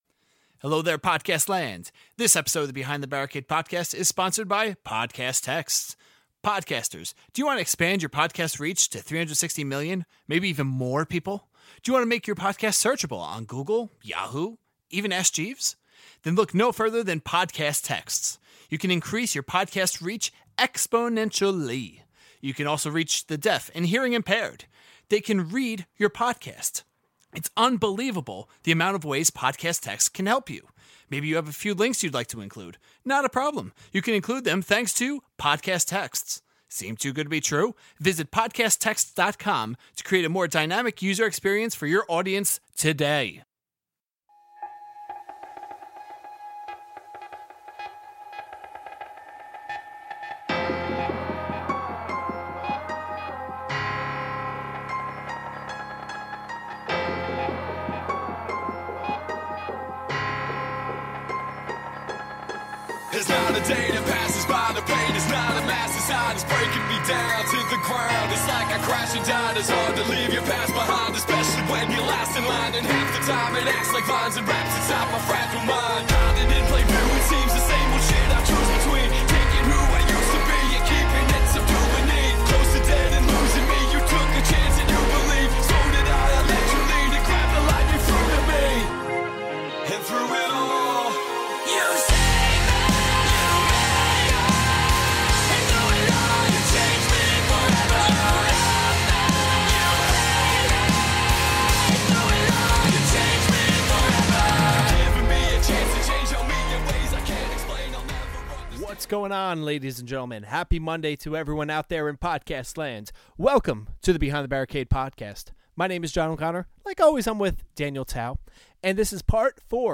Pre-Interview Song: Through it All Post-Interview Song: Lost and Alone For more information on